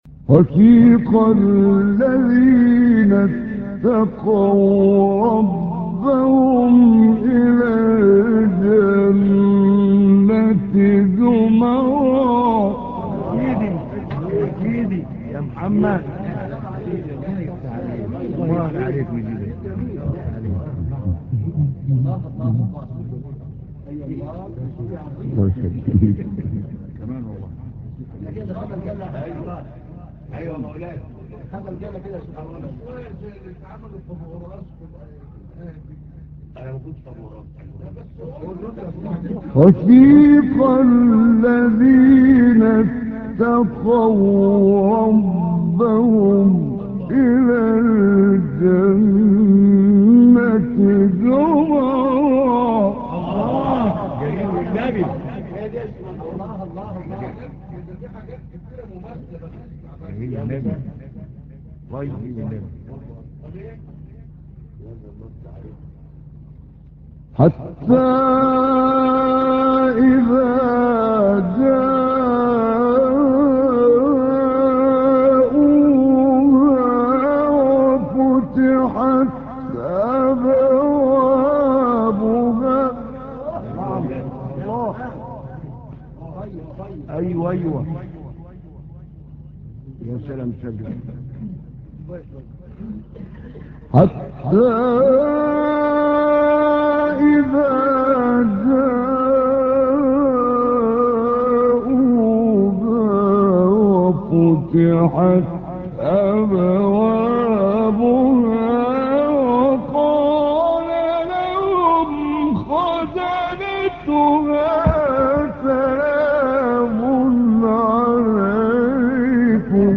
مقام : رست